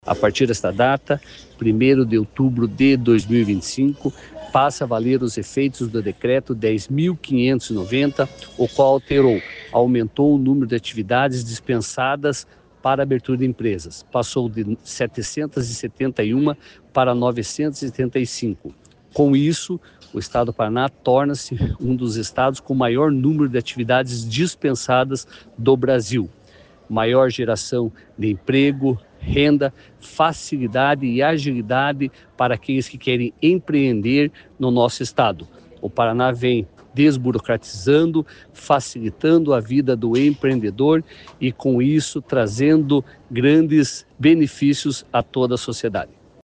Sonora do presidente do Comitê Permanente de Desburocratização da Casa Civil, Jean Puchetti, sobre a dispensa de alvarás e licenças para atividades econômicas consideradas de baixo risco